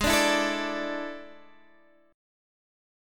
AM7sus4/Ab chord